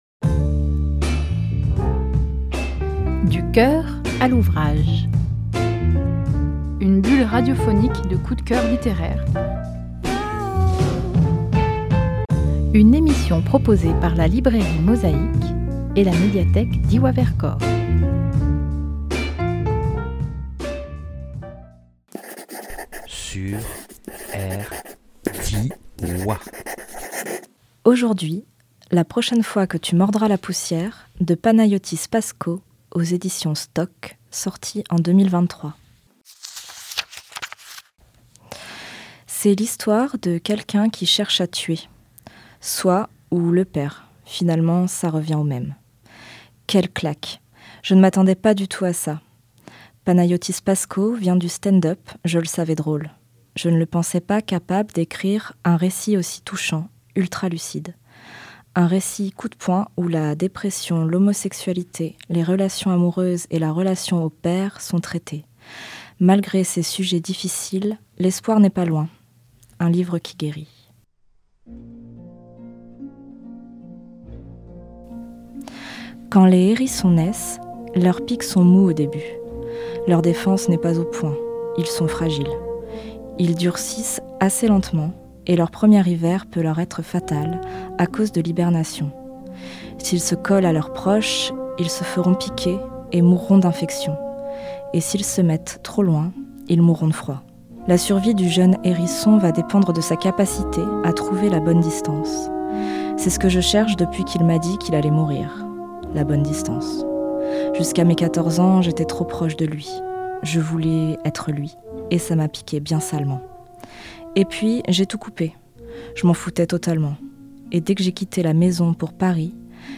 Studio Rdwa – avril 24